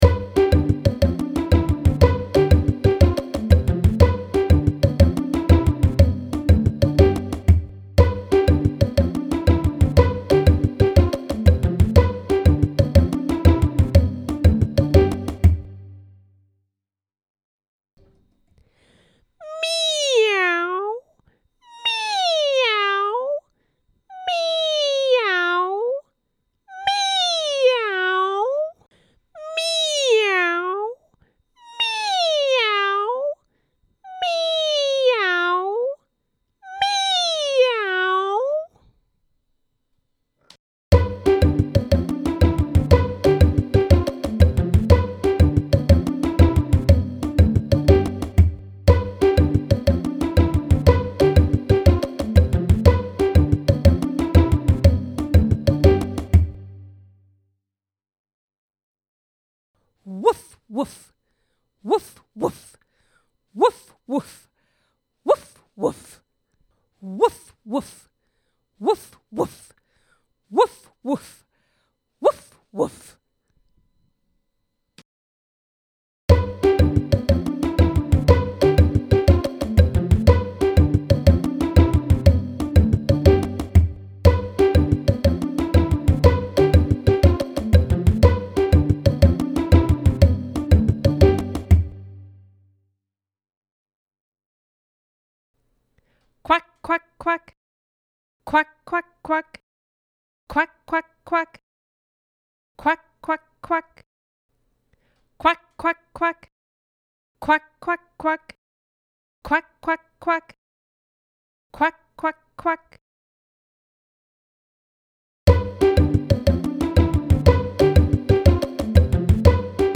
קבצי שמע לתרגול (שירי נגינה עליזים ומלאי מוטיבציה) –
Track-12-Animal-Sounds.mp3